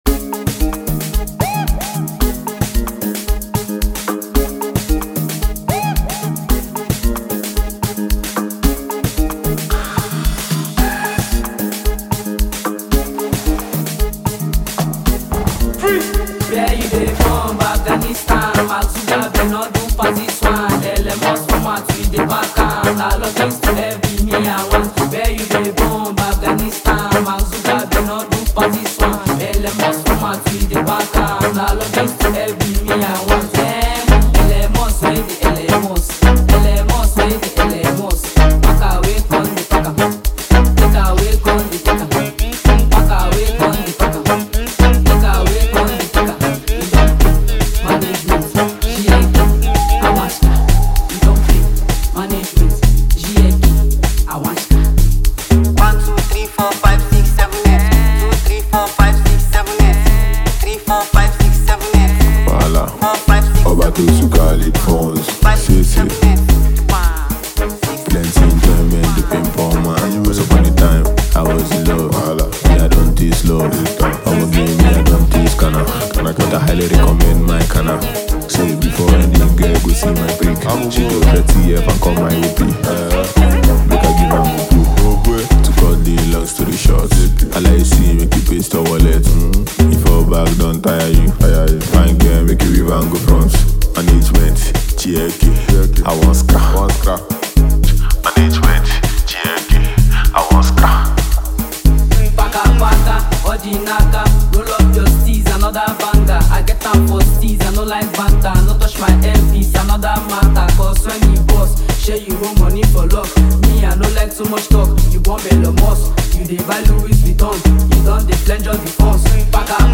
Buzzing Talented Nigerian singer